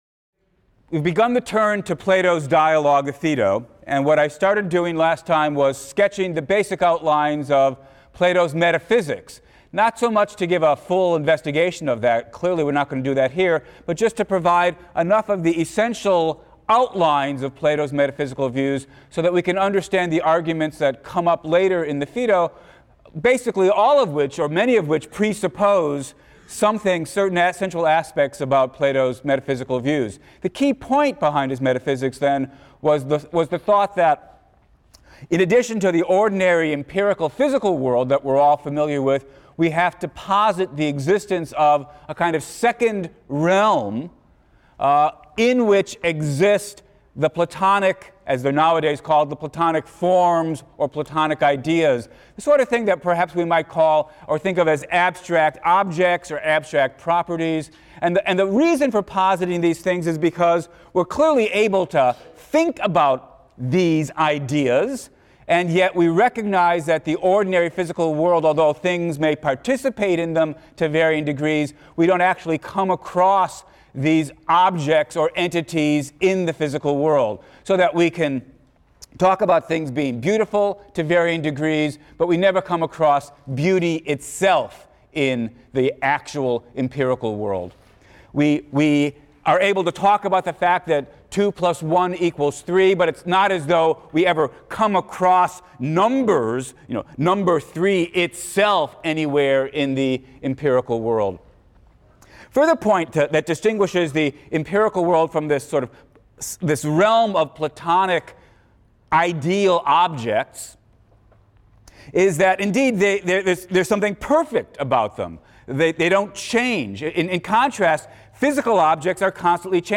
PHIL 176 - Lecture 7 - Plato, Part II: Arguments for the Immortality of the Soul | Open Yale Courses